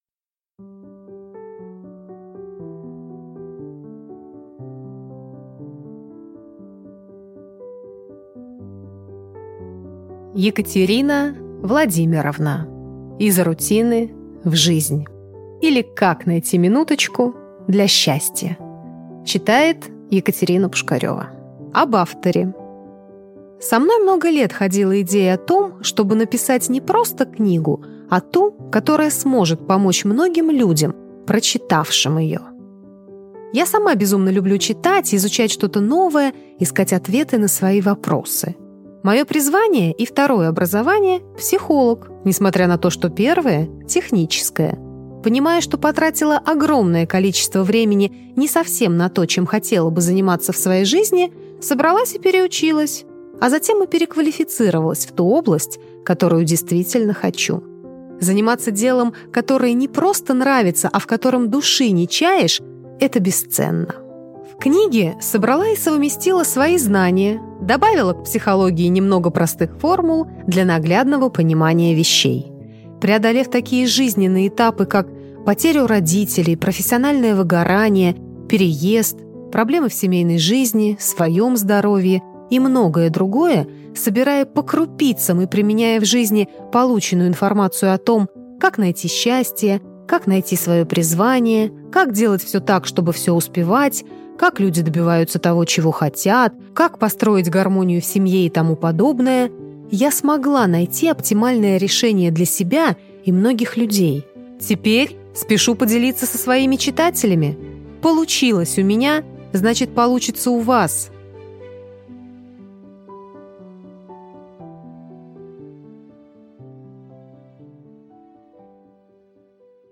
Аудиокнига Из рутины в жизнь | Библиотека аудиокниг